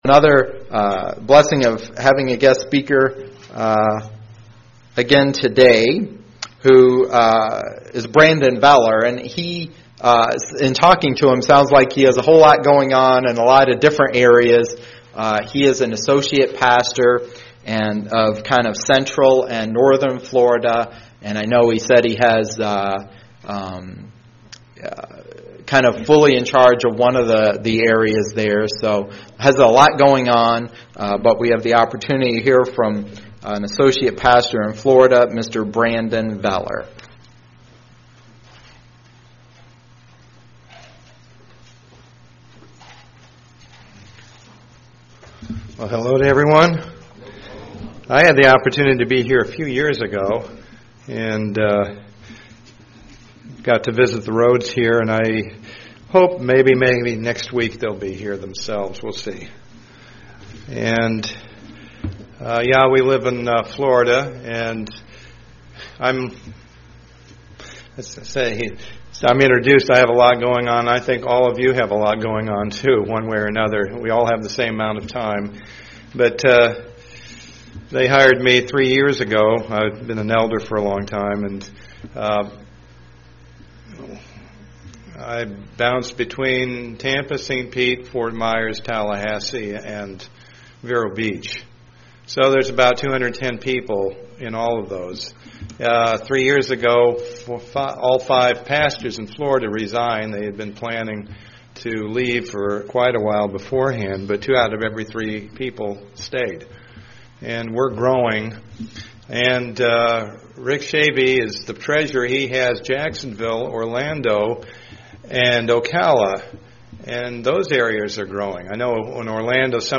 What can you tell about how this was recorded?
Given in Lansing, MI